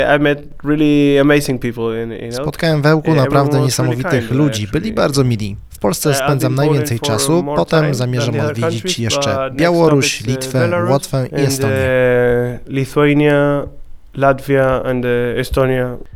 W rozmowie z reporterem Radia 5 przyznaje, że miasto spodobało mu się na tyle, że przedłużył swój pobyt.